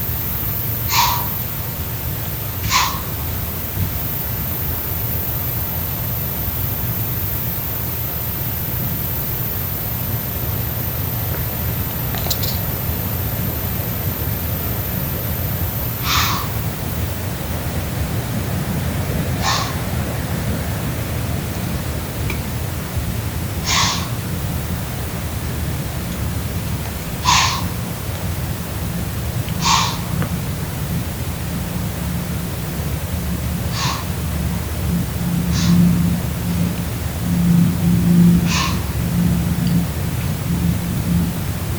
Whitetail Deer at 5am
buck-snort.mp3